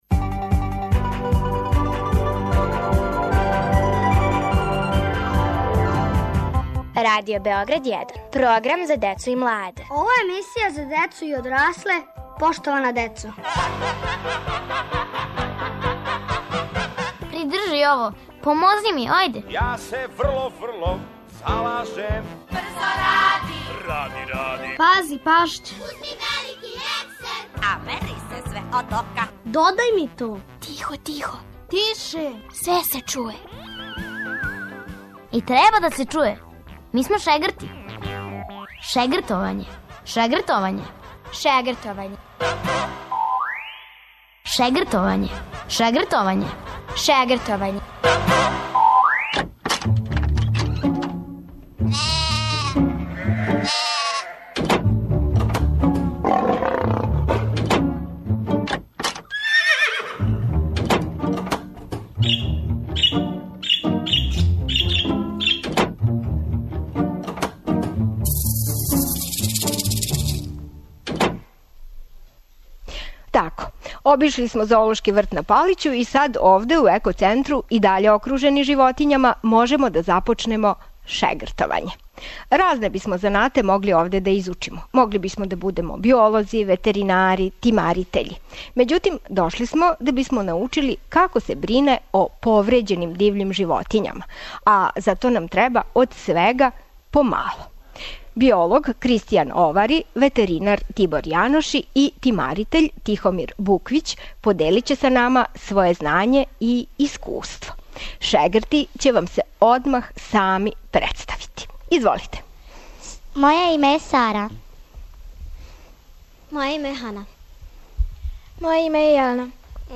Емисију реализујемо из Зоолошког врта на Палићу.